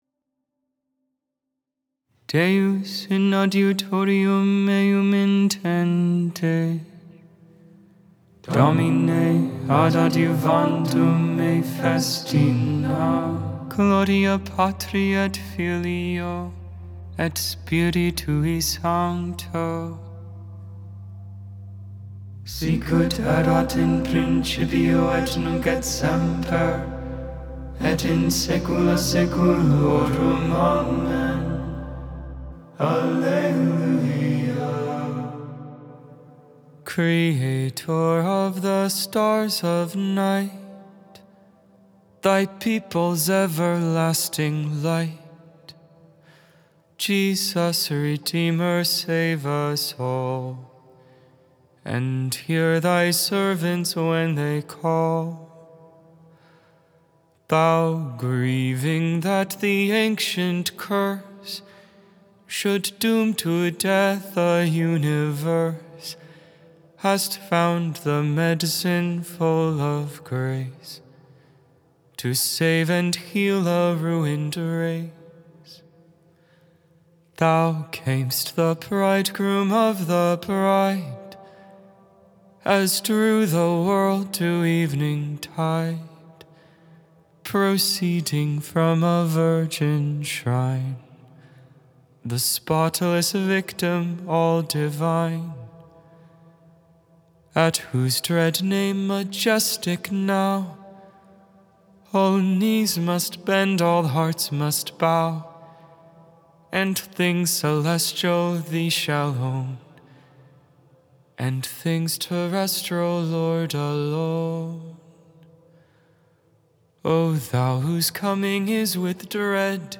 Vespers, Evening Prayer for the 1st Thursday in Advent, December 5, 2025.